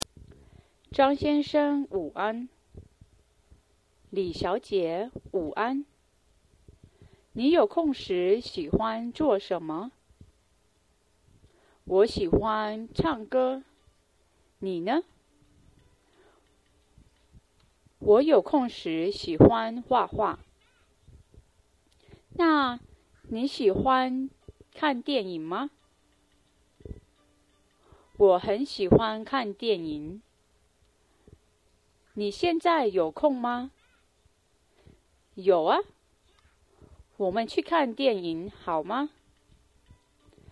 Hope it doesn't get you too confused.
Lesson6-conversation.mp3